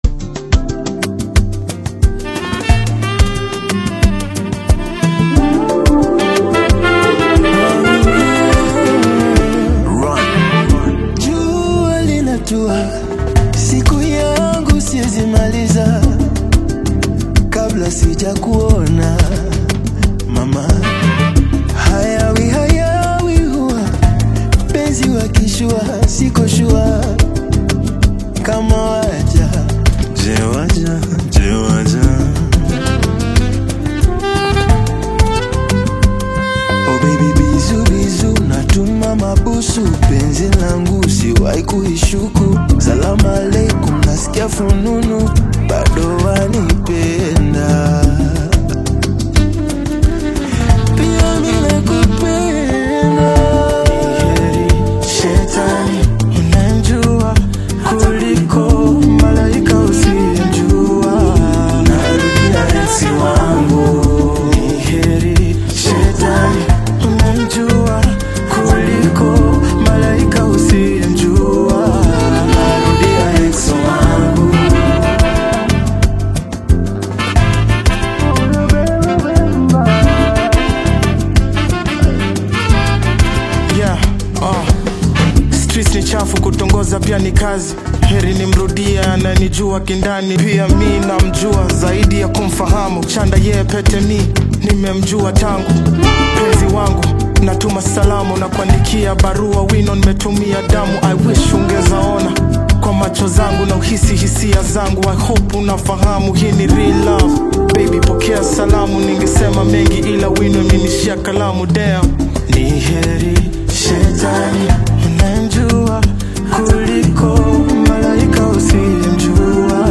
AudioKenyan Music
Afro-fusion single
Kenyan duo